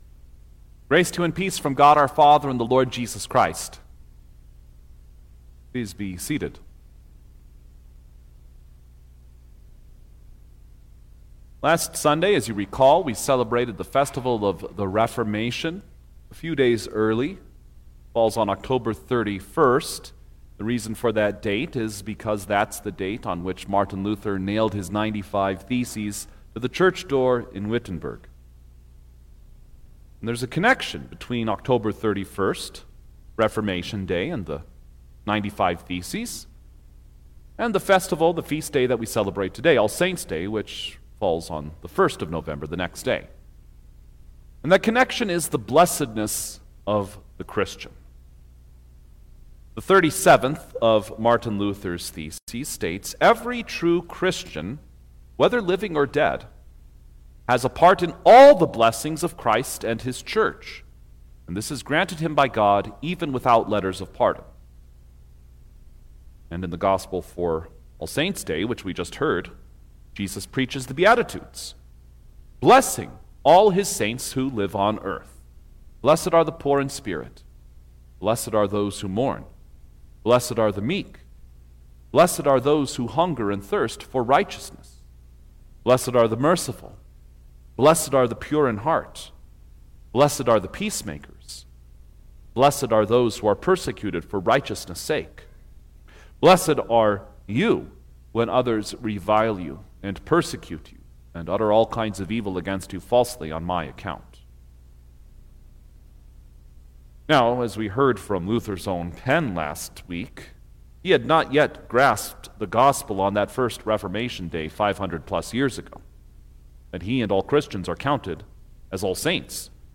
November-2_2025_All-Saints-Day_Sermon-Recording-Stereo.mp3